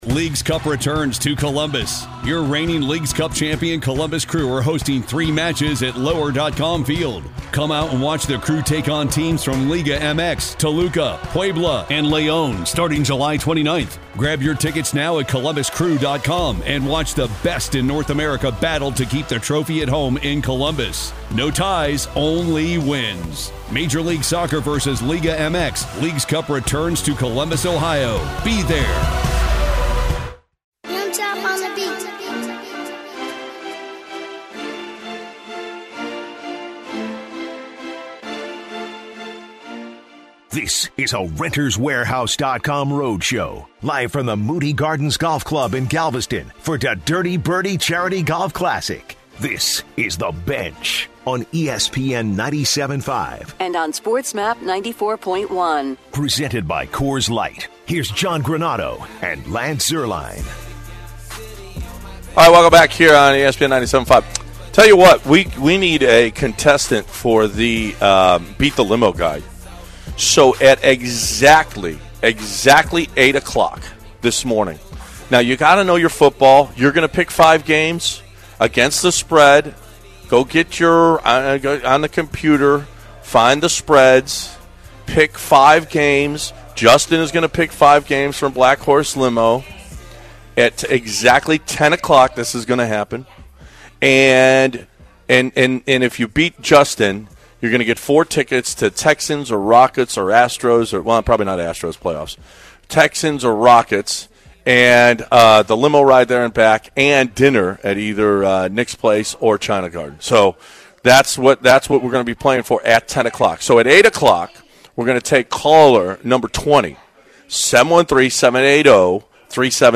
broadcasting live from the Da Dirty Birdie Charity Golf Tournament at Moody Garden in Galveston, Tx. The guys talk football about the Los Angeles Rams and how they improved this season. They discuss New Yankees pitcher CC Sabathia being ejected for the second time this season and two innings shy of a $500,000 bonus. They discuss the final weekend games for the Astros against the Baltimore Orioles and which Astros pitchers will make the postseason roster.